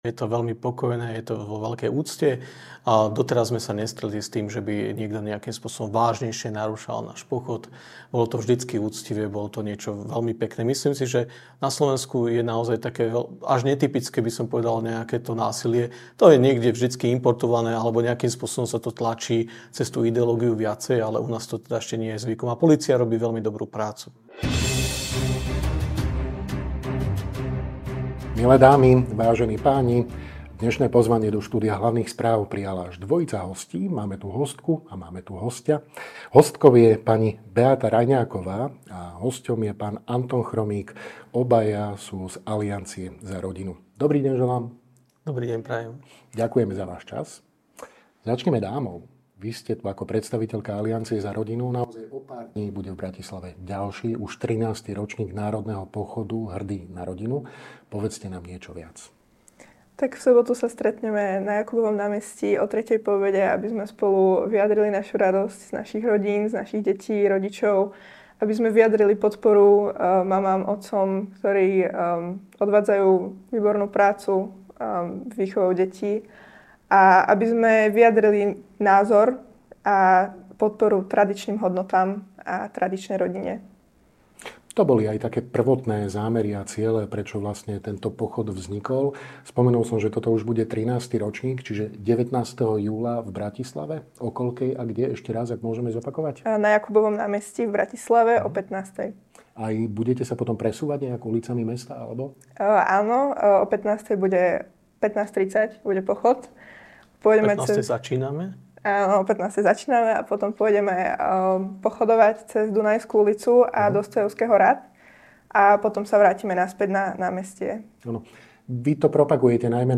NAŽIVO